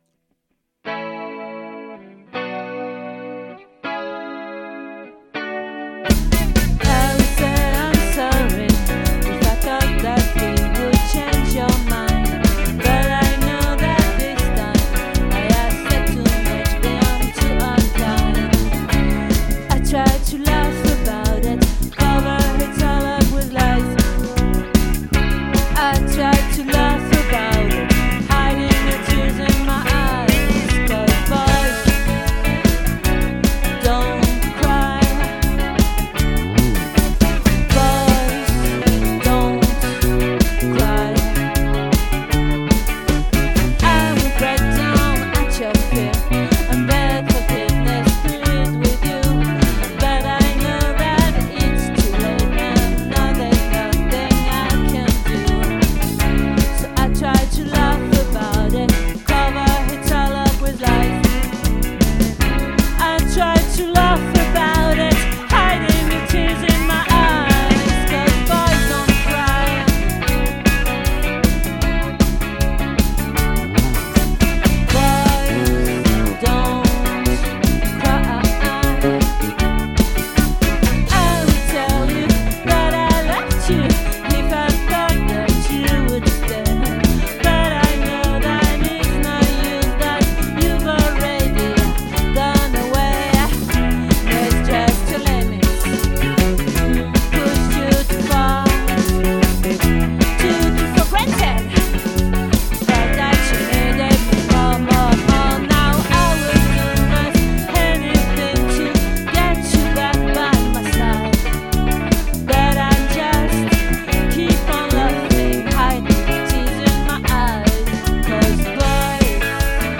🏠 Accueil Repetitions Records_2022_11_09